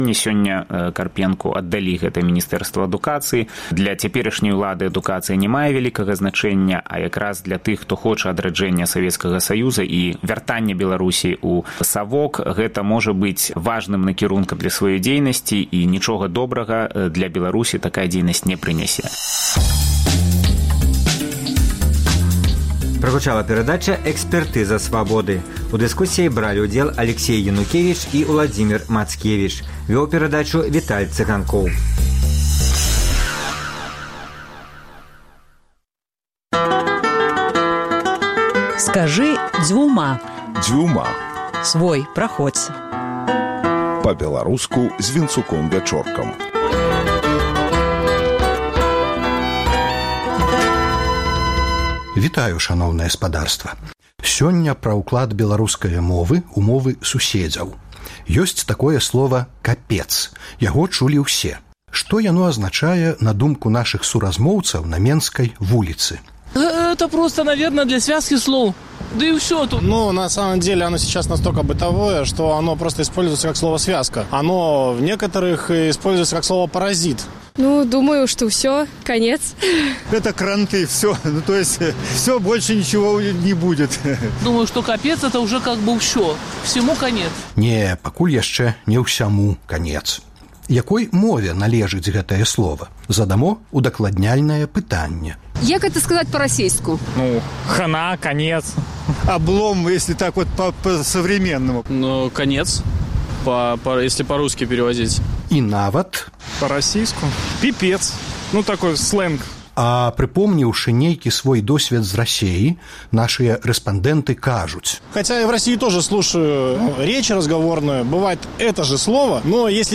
Што пра капец ведаюць менчукі? Аўдыёвэрсія вулічнага апытаньня.